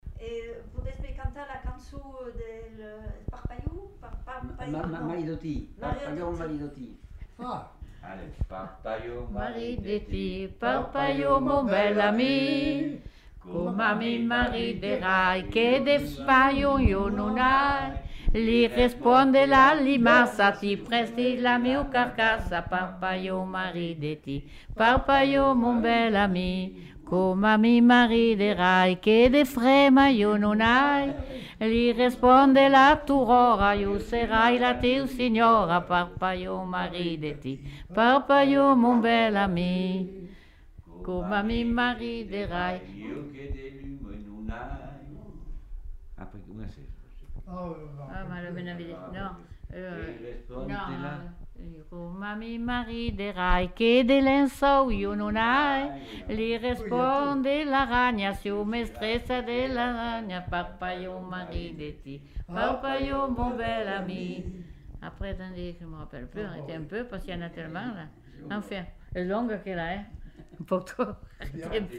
Aire culturelle : Provence
Lieu : Coaraze
Genre : chant
Effectif : 3
Type de voix : voix d'homme ; voix de femme
Production du son : chanté
Description de l'item : fragment ; 3 c. ; refr.